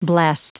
Prononciation du mot blessed en anglais (fichier audio)
Prononciation du mot : blessed